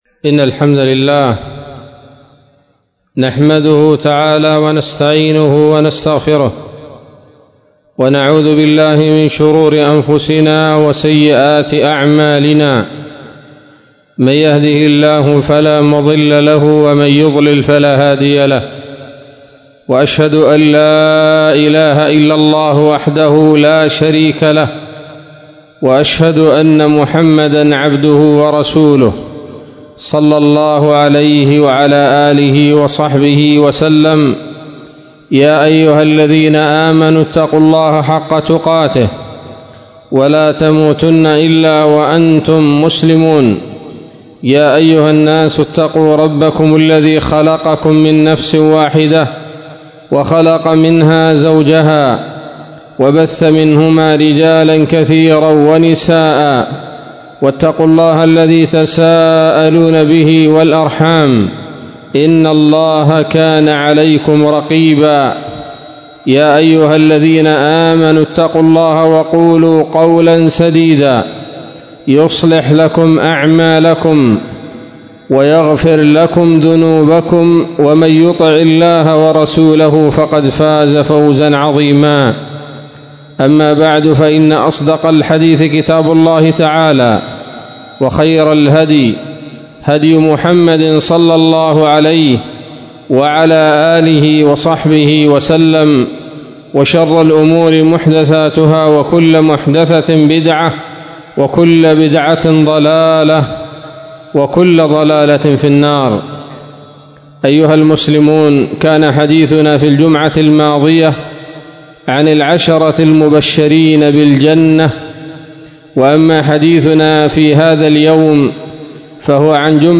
خطبة بعنوان : ((المبشرون بالجنة [2])) 03 رجب 1438 هـ